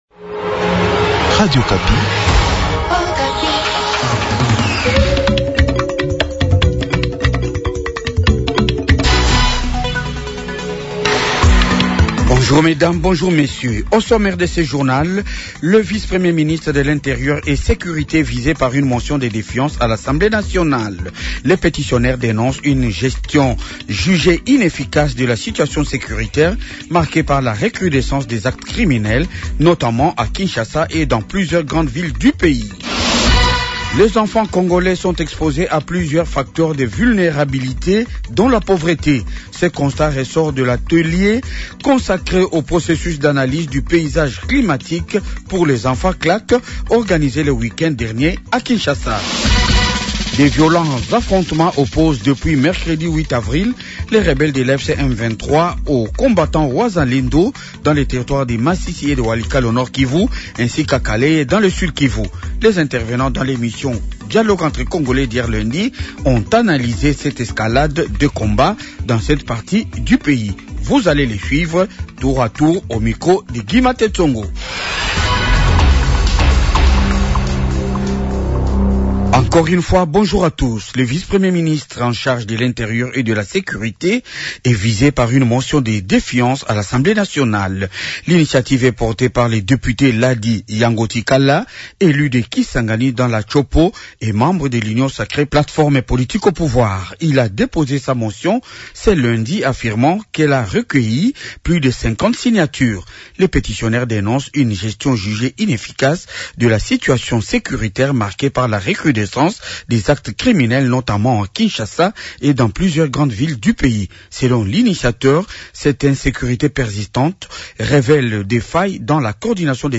Journal du matin 8h